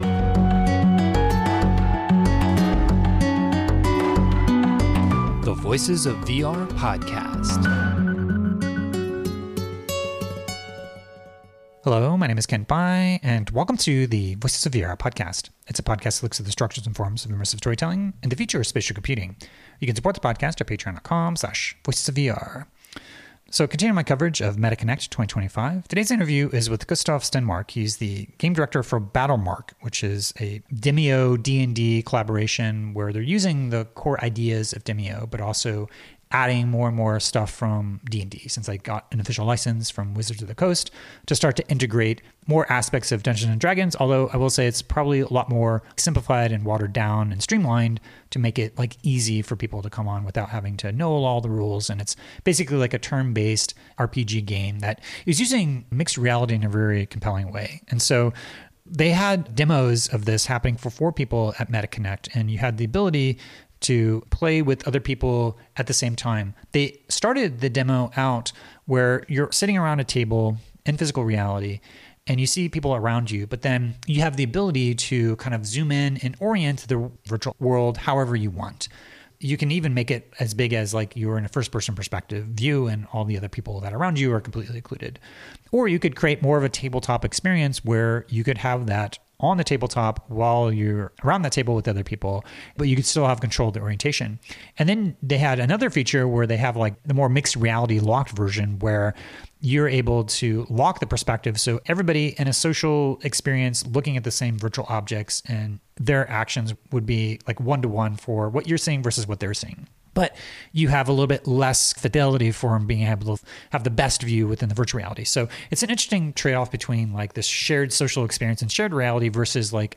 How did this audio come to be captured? I did an interview